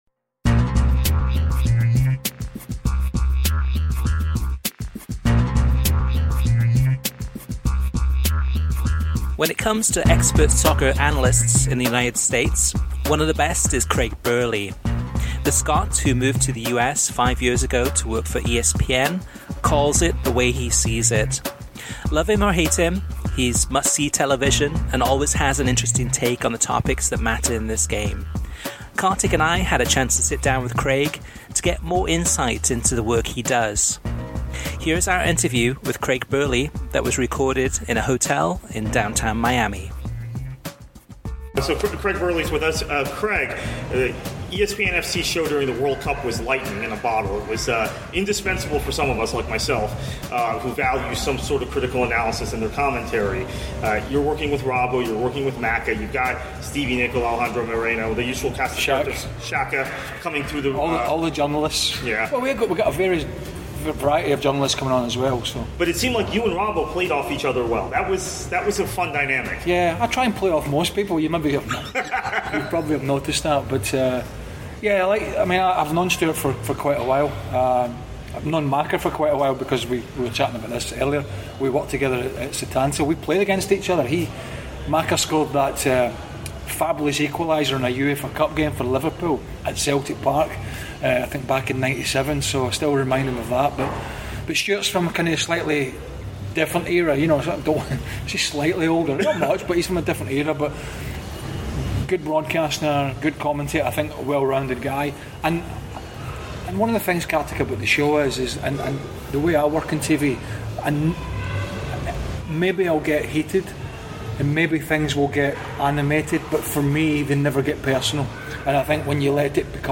craig-burley-interview.mp3